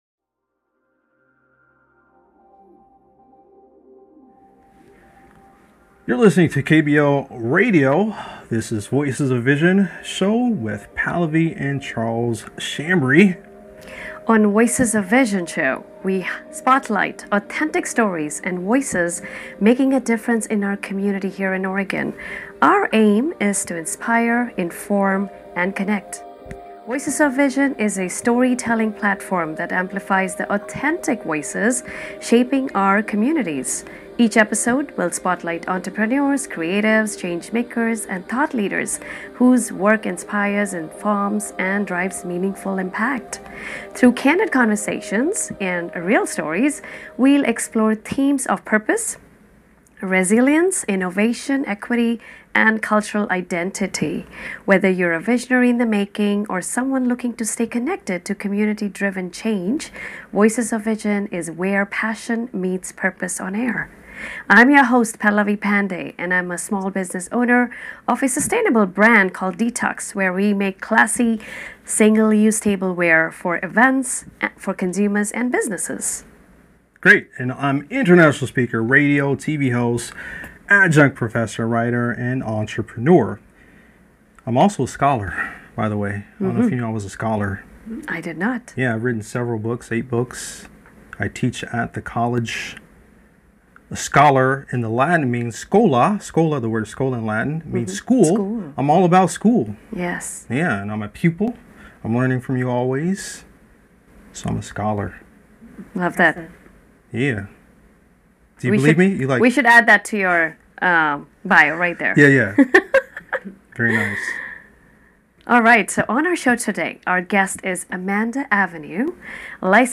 Radio Schedule